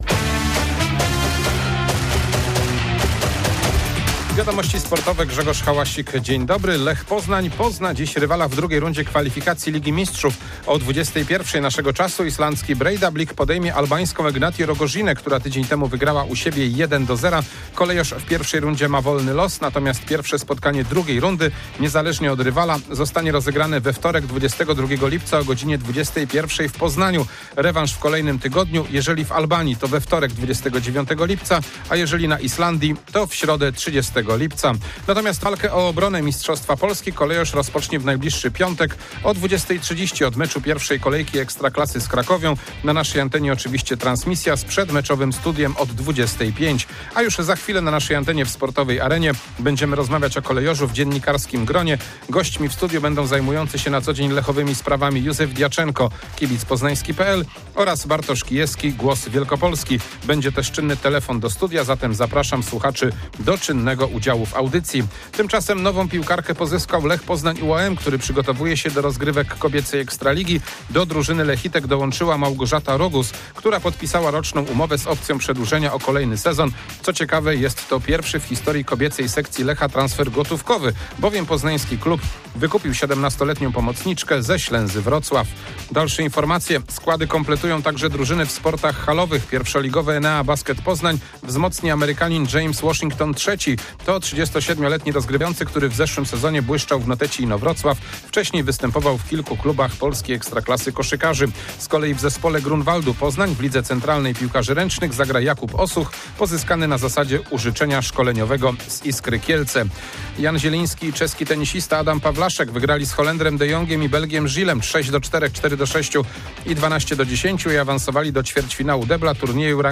15.07.2025 SERWIS SPORTOWY GODZ. 19:05